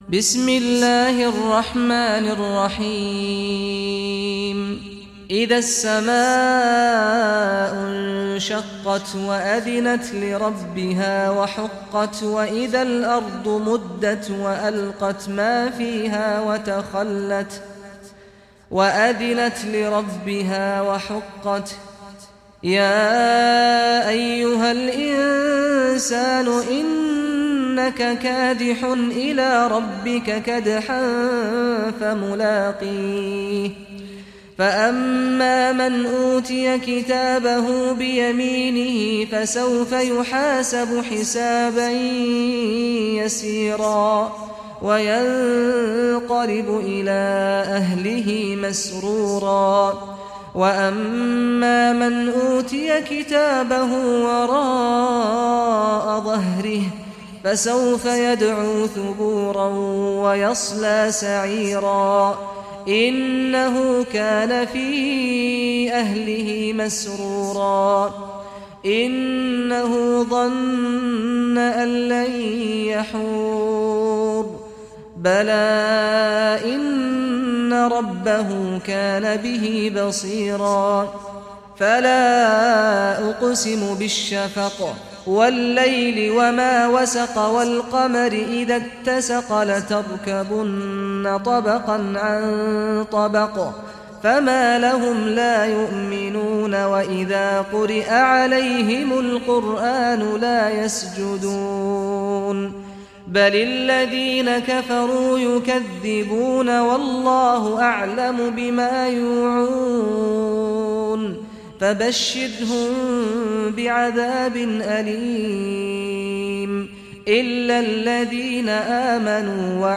Surah Al Inshiqaq Recitation by Sheikh Saad Ghamdi
Surah Al Inshiqaq, listen or play online mp3 tilawat / recitation in Arabic in the beautiful voice of Sheikh Saad al Ghamdi.